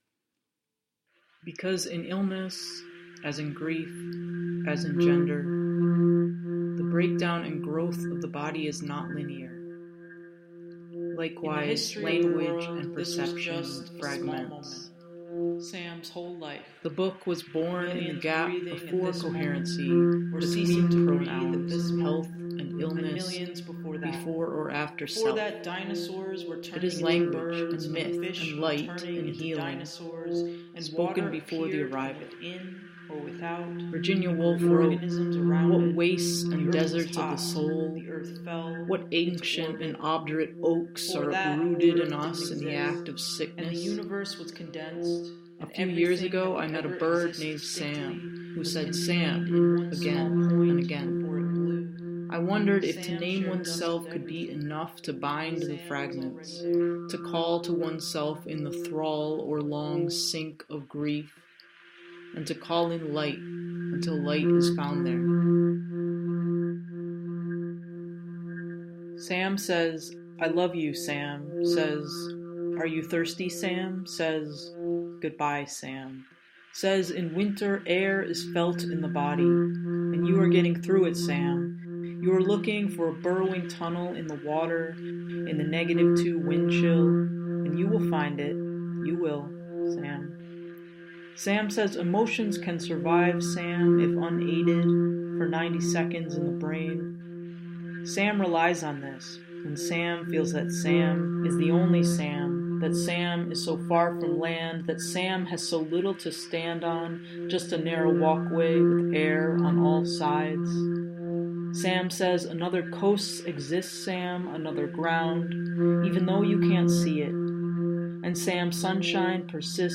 Voice, sounds, recording and mixing